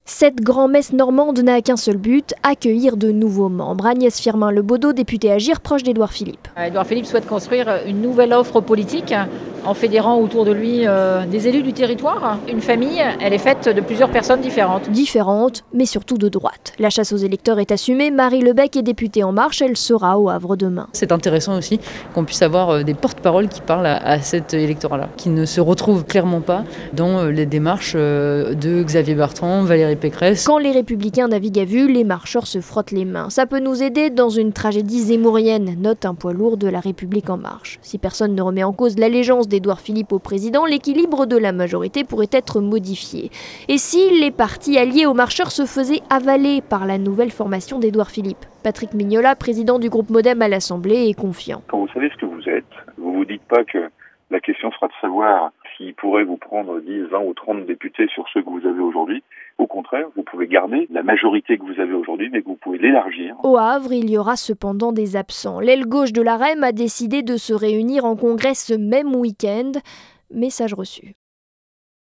Politique